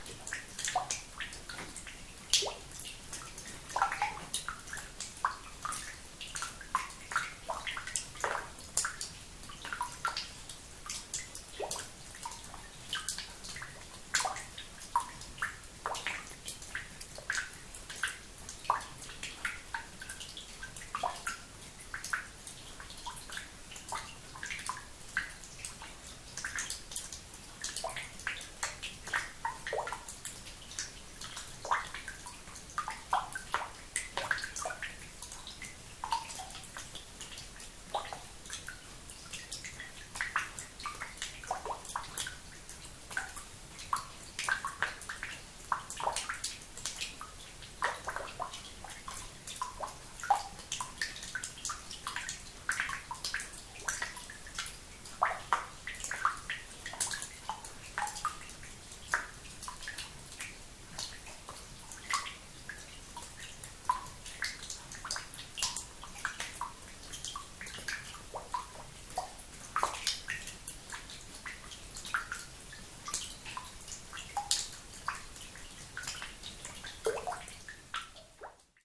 Vous pouvez écouter cette carrière ci-dessous, c'est une pissouille minière.